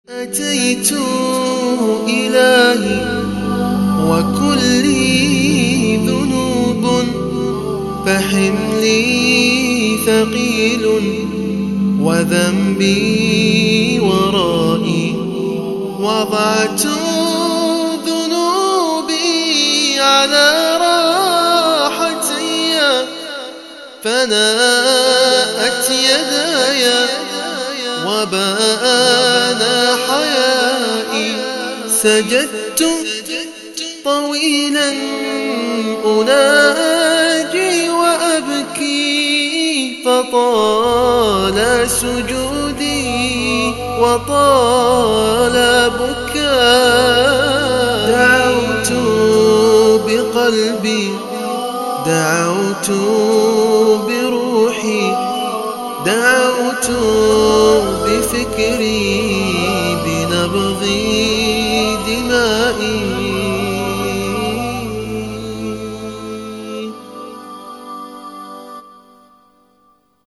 من هو هذا المنشد :